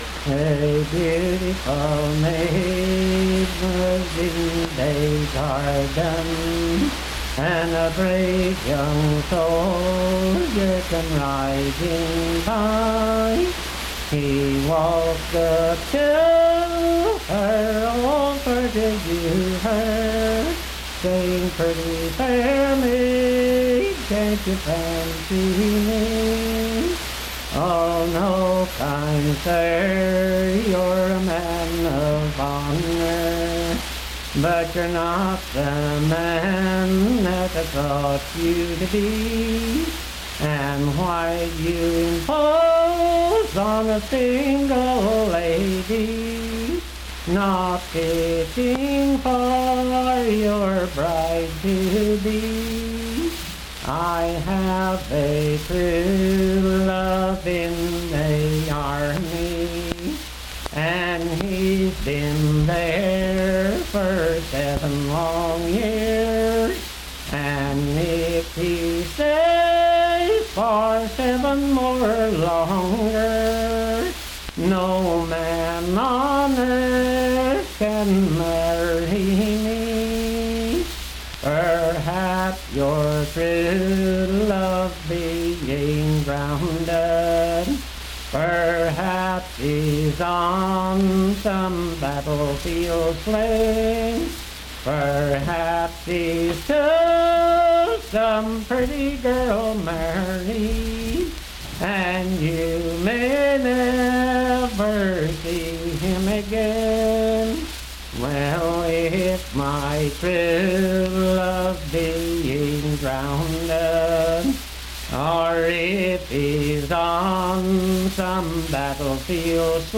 Voice (sung)
Randolph County (W. Va.)